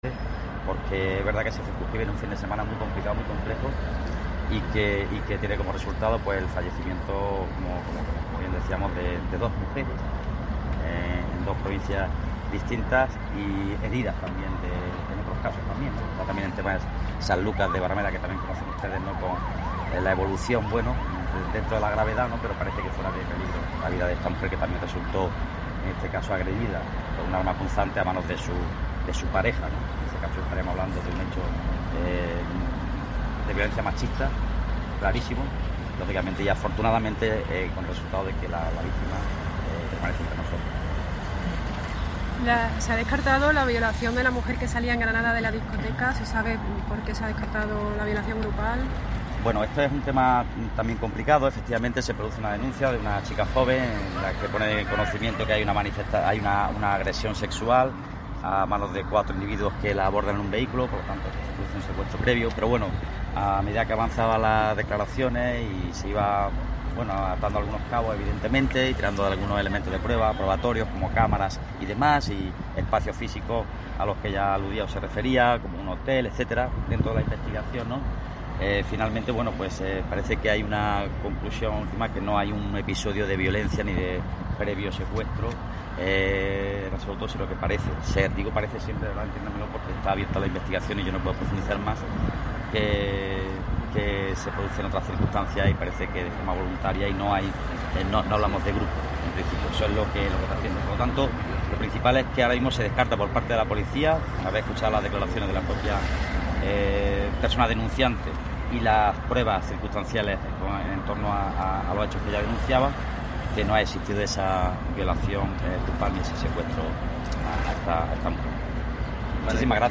Escucha a Pedro Fernández, delegado del Gobierno de España en Andalucía